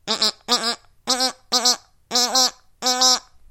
Звуки включают крики, шум шагов и другие характерные для эму аудиоэффекты.
Мультипликационная версия